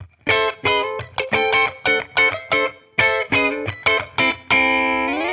Index of /musicradar/sampled-funk-soul-samples/90bpm/Guitar
SSF_TeleGuitarProc2_90A.wav